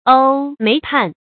侜張為幻 注音： ㄓㄡ ㄓㄤ ㄨㄟˊ ㄏㄨㄢˋ 讀音讀法： 意思解釋： 侜張：欺騙作偽。用欺騙迷惑人。